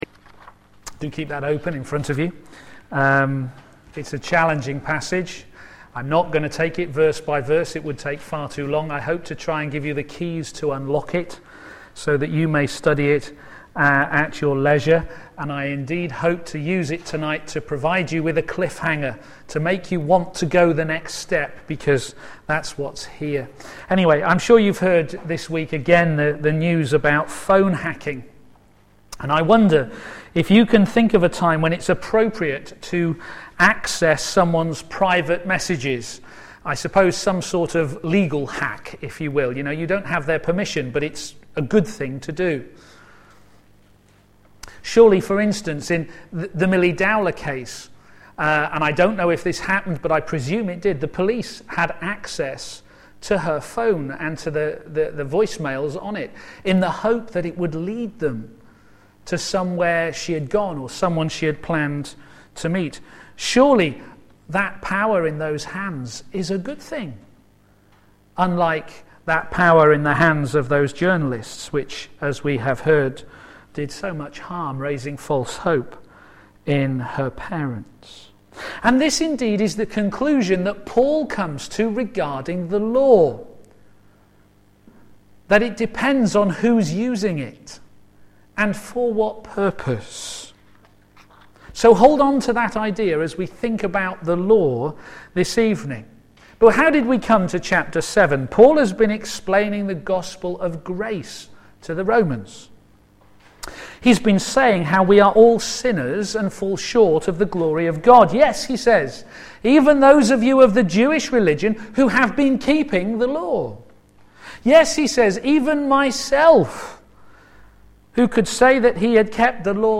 p.m. Service
Series: God's Righteousness Revealed Theme: God's righteousness revealed in our freedom from the Law Sermon